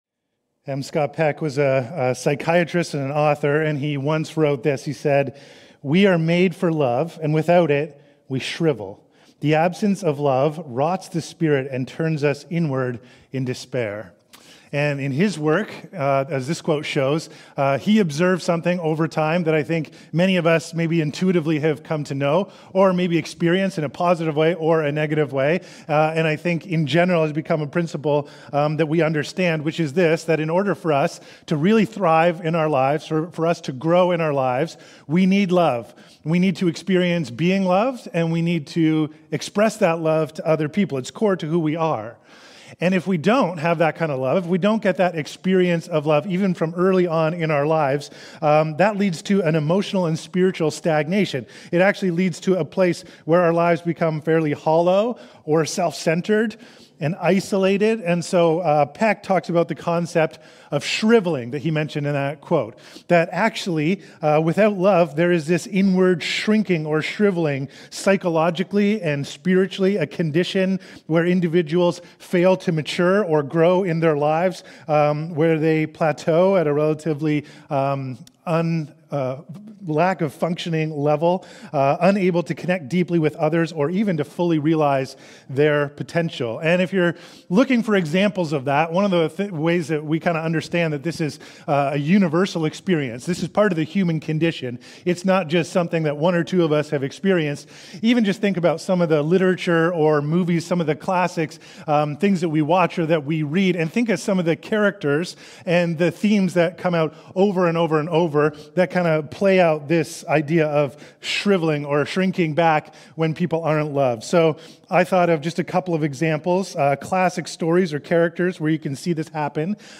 Sermons | Westside Church